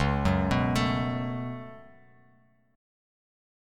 Db7sus4#5 chord